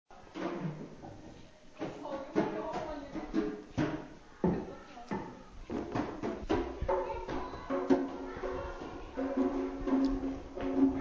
Klick auf das Bild, dann hörst Du das Trommeln!
trommeln.mp3